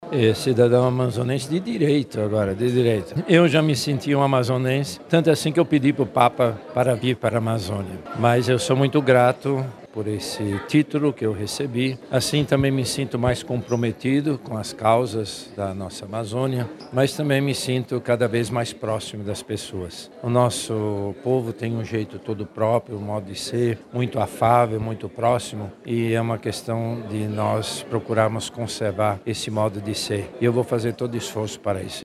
A sessão especial contou com a presença de autoridades civis, representantes da Arquidiocese de Manaus, padres do clero Arquidiocesano, religiosos, agentes de pastorais, serviços e movimentos e a imprensa.